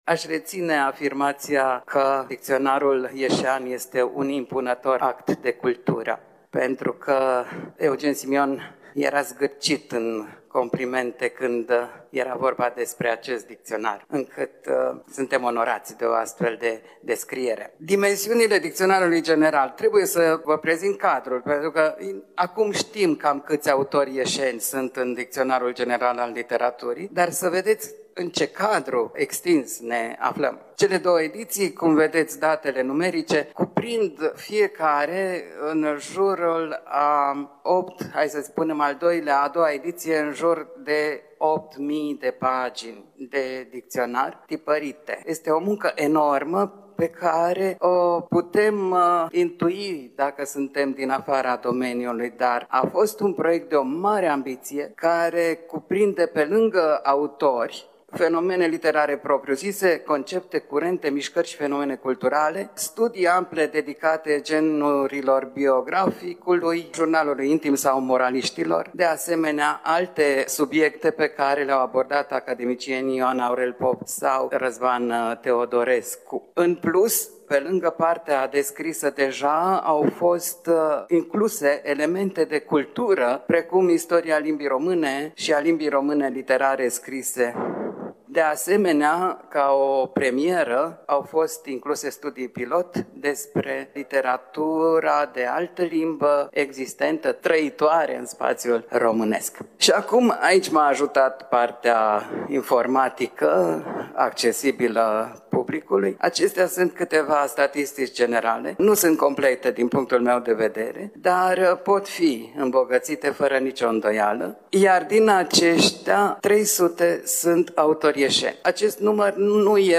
La Iași, în perioada 4–5 septembrie 2025, s-a desfășurat Conferința anuală a Muzeului Municipal „Regina Maria”, manifestare aflată la ediția a VI-a. Conferința s-a intitulat Din istoria orașului Iași: Oameni, Locuri, Instituții.